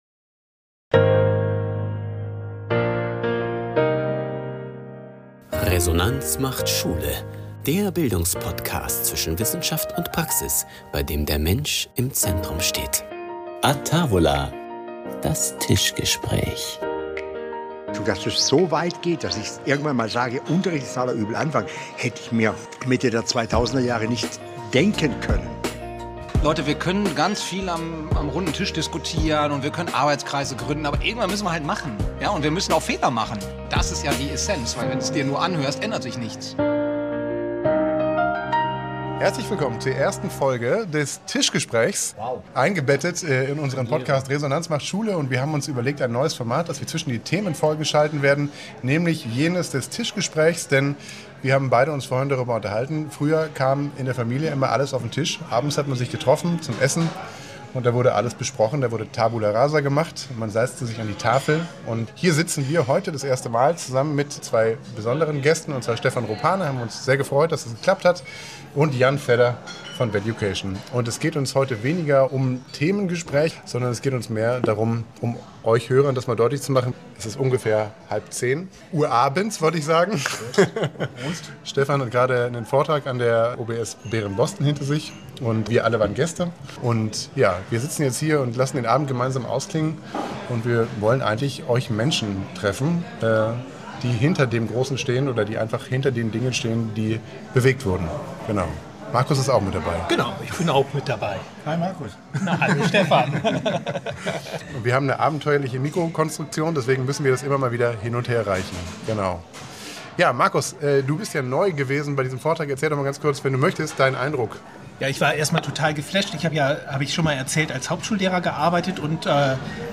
„A tavola!“ verlagert das Gespräch von der Wandtafel an die gedeckte Tafel.
Mit italienischem Verve – a tavola! – kommen Genuss, Offenheit und ein bewusst lockeres Gesprächssetting zusammen.
Es geht um Motivation, Biografie, Zweifel, Entscheidungen – und darum, was Schulentwicklung wirklich antreibt: Menschen. Kein Vortrag.